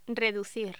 Locución: Reducir
voz
Sonidos: Voz humana